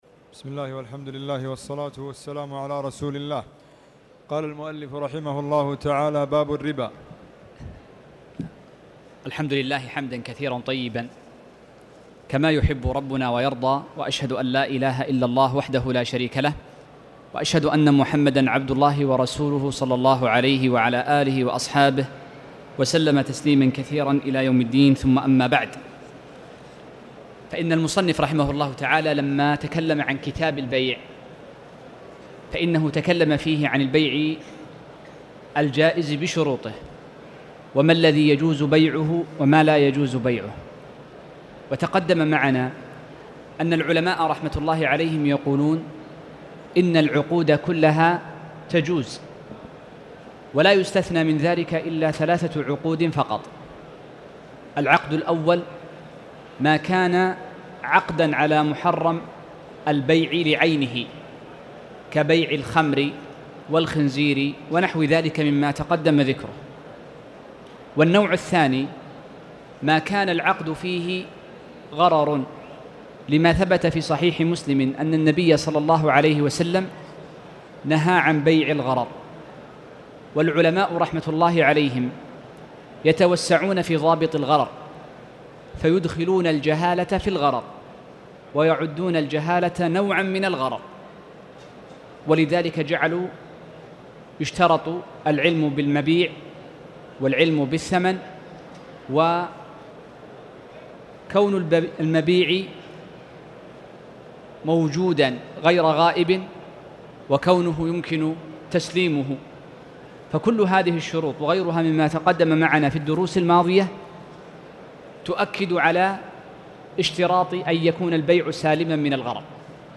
تاريخ النشر ٣٠ ربيع الثاني ١٤٣٨ هـ المكان: المسجد الحرام الشيخ